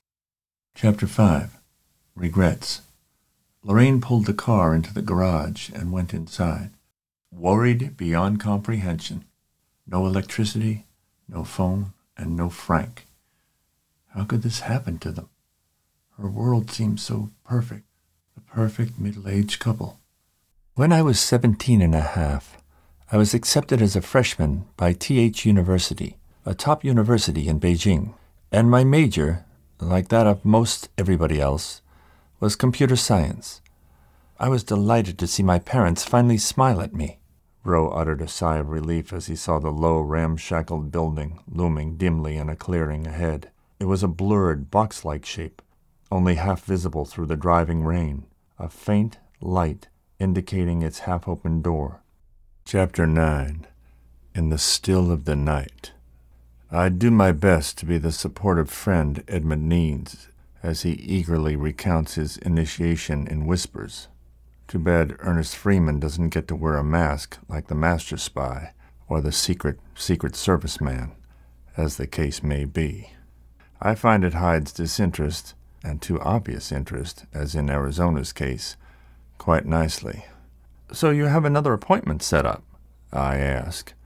Male
English (North American)
Adult (30-50), Older Sound (50+)
The vocal brand I can offer you is primarily intelligent, friendly, and encouraging.
My narration can be deep baritone or older sage and wise sounding - a wide vocal range.
Narration Demos
All our voice actors have professional broadcast quality recording studios.
0105WM_Narration_Demo_Reel.mp3